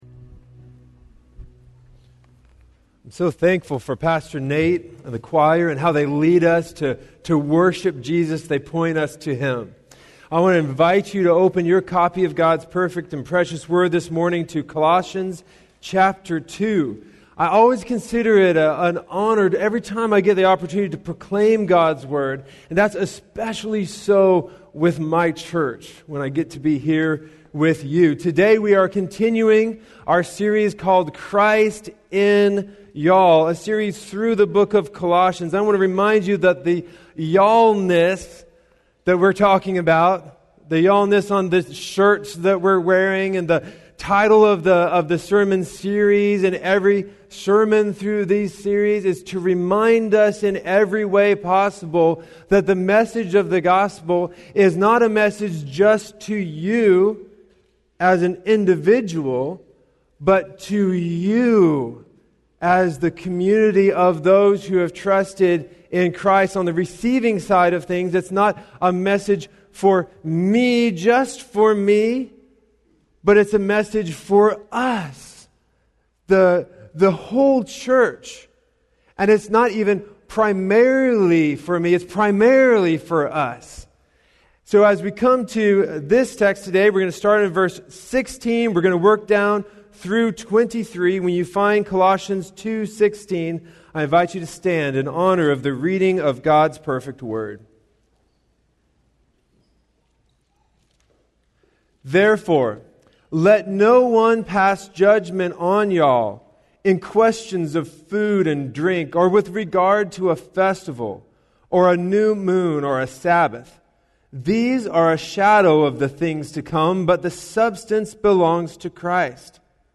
In today's sermon we see the treasure of being rooted in Christ and His family, the church.